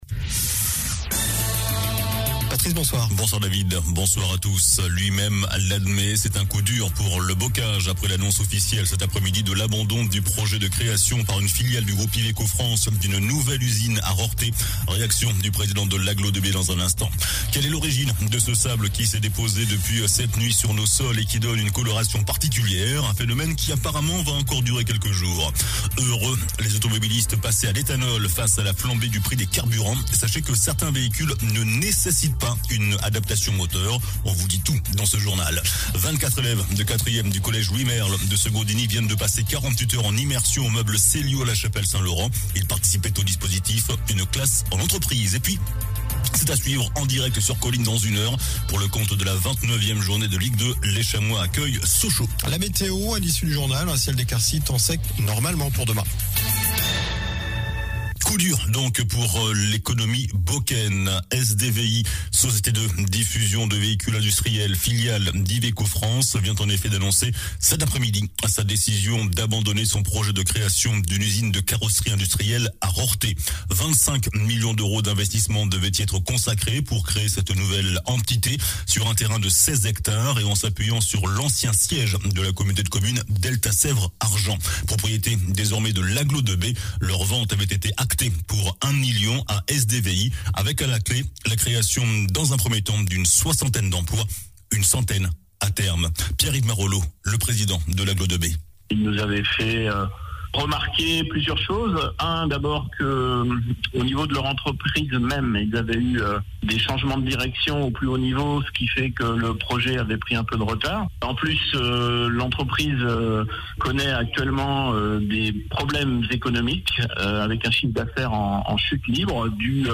JOURNAL DU MARDI 15 MARS ( SOIR )